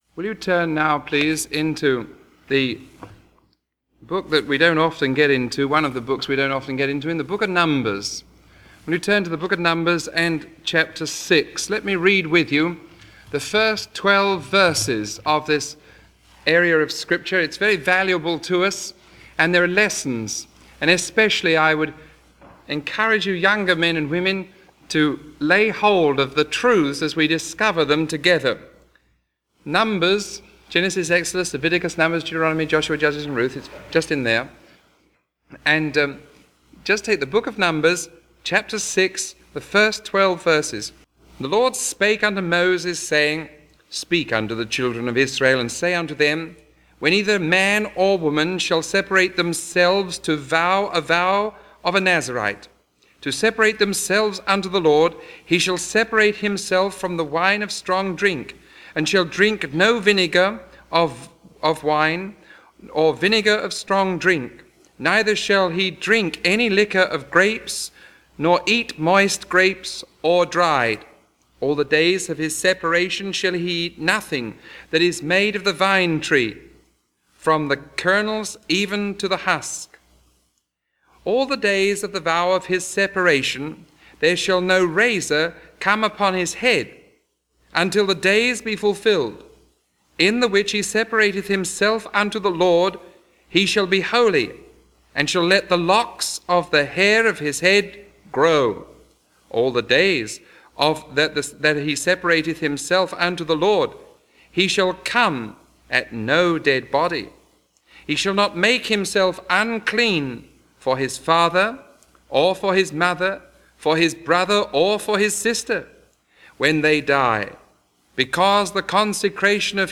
Sermon 0028B recorded on March 11